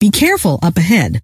Index of /sc/sound/scientist_female/